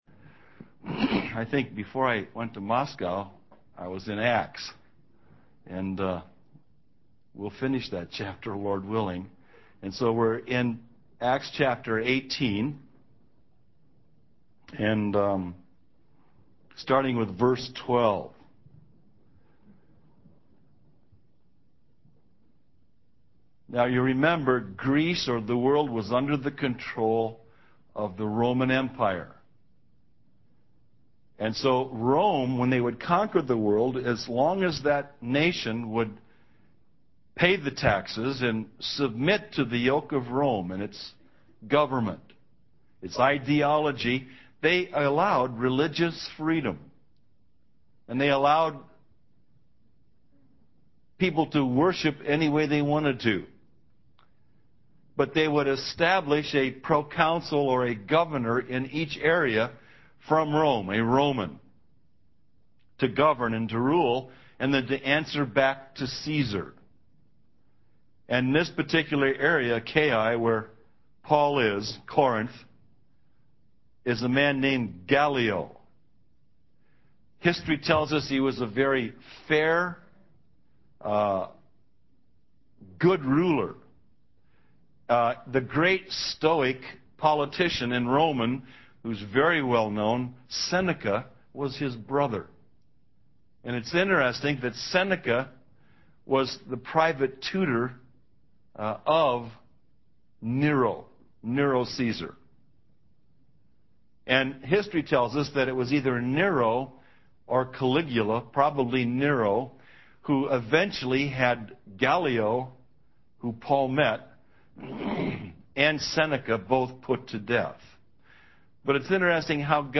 In this sermon, the speaker shares a personal experience of a car accident where he miraculously survived without any injuries.